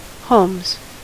Ääntäminen
Vaihtoehtoiset kirjoitusmuodot (slangi) holmes Ääntäminen US Tuntematon aksentti: IPA : /ˈhəʊmz/ IPA : /ˈhoʊmz/ Haettu sana löytyi näillä lähdekielillä: englanti Käännöksiä ei löytynyt valitulle kohdekielelle.